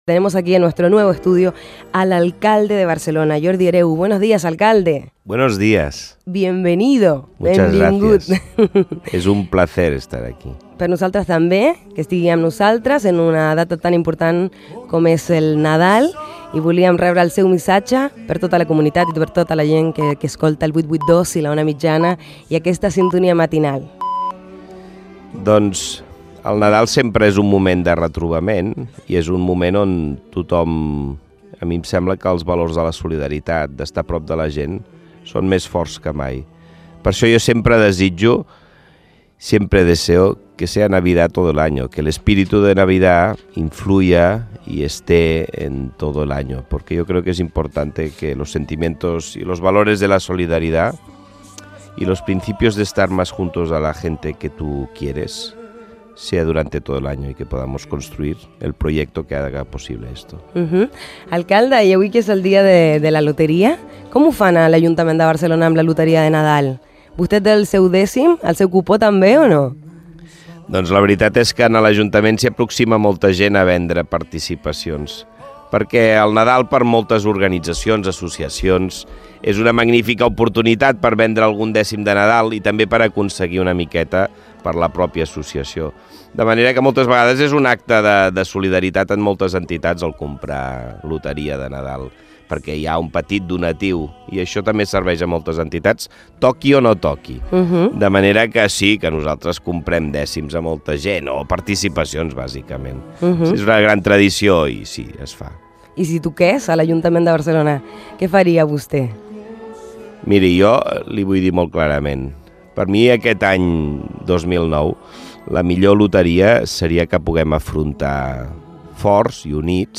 Sintonia matinal: Entrevista alcalde Jordi Hereu - COM Ràdio, 2008